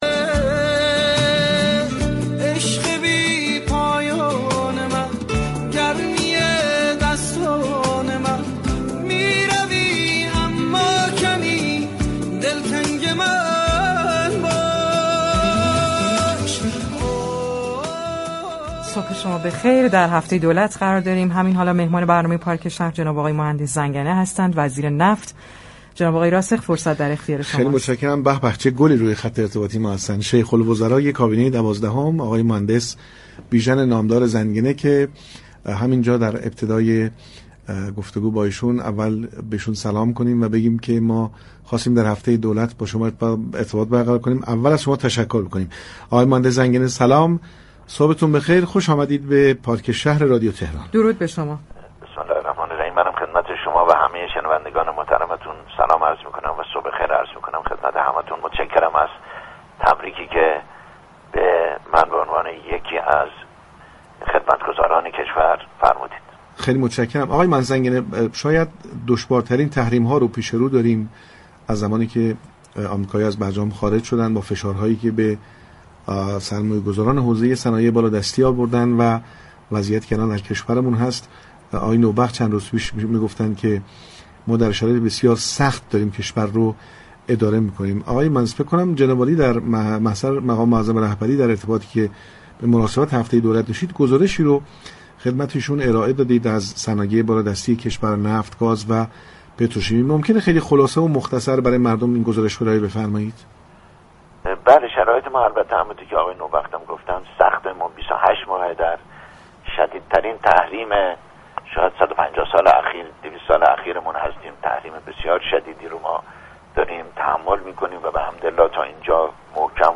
مهندس بیژن زنگنه، وزیر نفت دولت دوازدهم به مناسبت هفته دولت با ارائه گزارشی تفصیلی، اقدامات وزارتخانه متبوع خود طی سال گذشته را در برنامه پارك شهر رادیو تهران تشریح كرد.